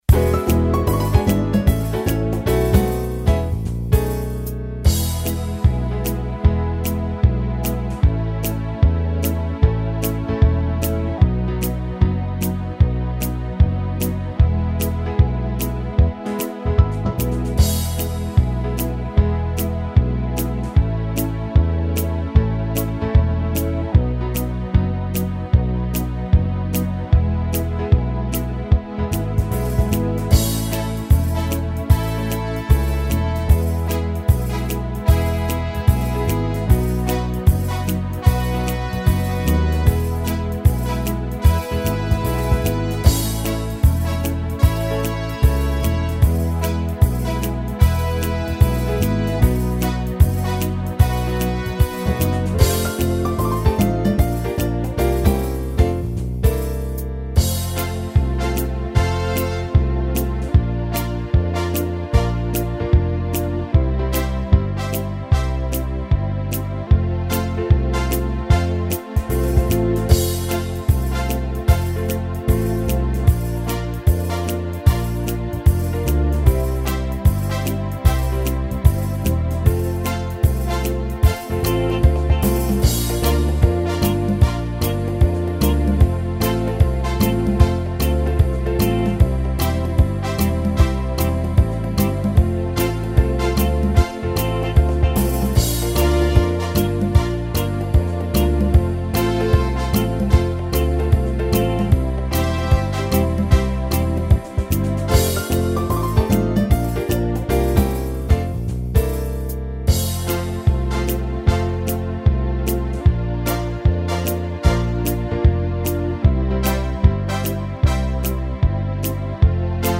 Playbacks-KARAOKE Zobrazení